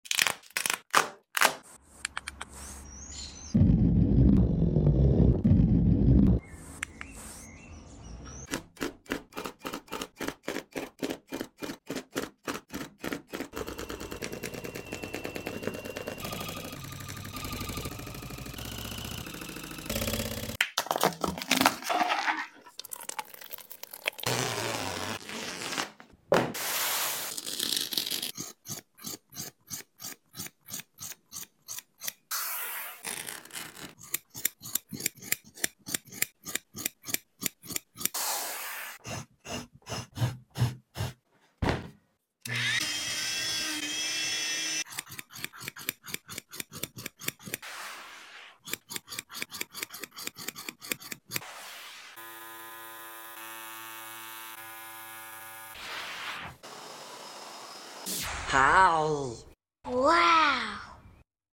Stitch rug ASMR! 💙🌺 sound effects free download